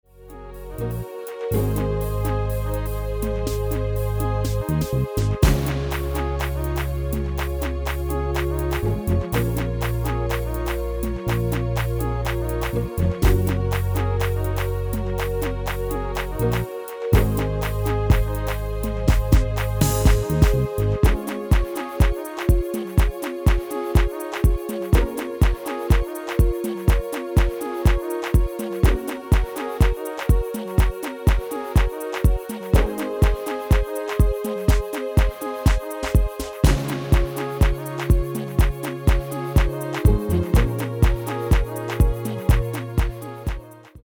Demo/Koop midifile
Genre: Actuele hitlijsten
Toonsoort: Db
- Géén vocal harmony tracks